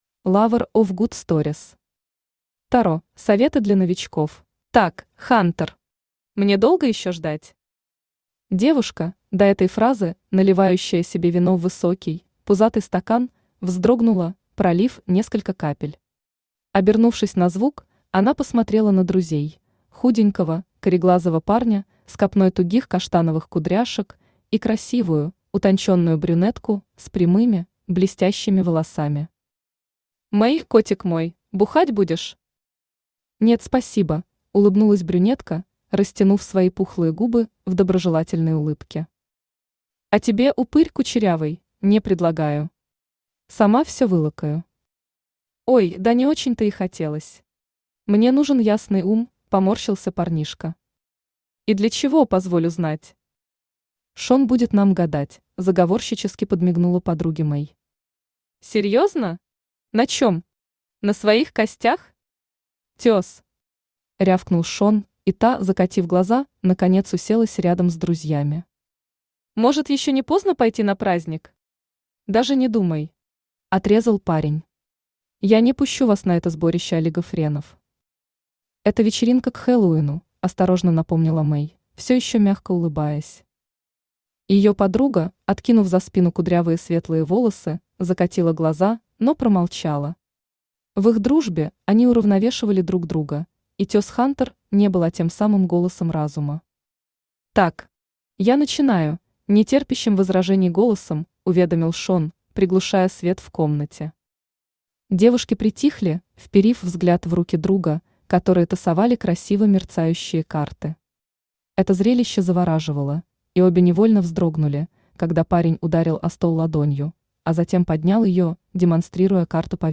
Aудиокнига Таро: советы для новичков Автор Lover of good stories Читает аудиокнигу Авточтец ЛитРес.